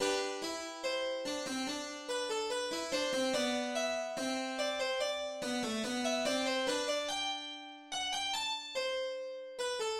Sol majeur
Voix 3
Ce diptyque en sol majeur forme un numéro plein de charme et de simplicité. La petite fugue sans prétention qui suit son prélude carillonne ses guirlandes d'arpèges.